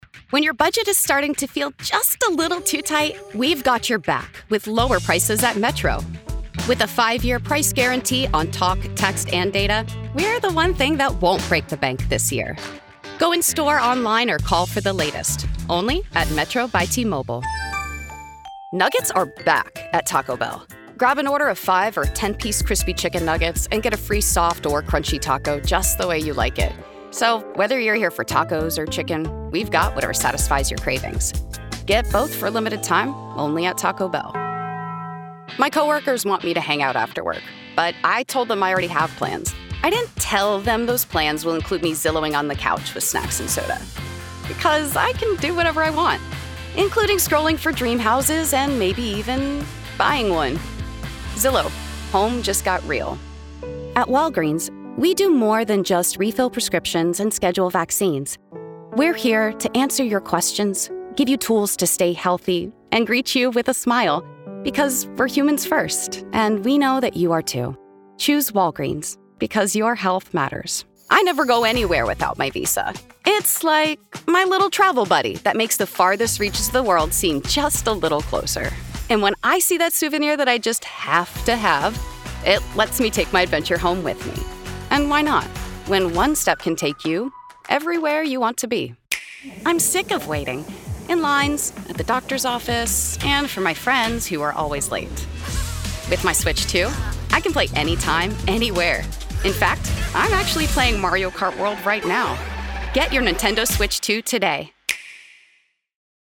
Commercial Demo - 2025
Accents and Dialects
General American; Californian (Valley girl); New York/New Jersey; Trans-Atlantic; Southern (Texas, Georgia, etc.); RO British; generic Russian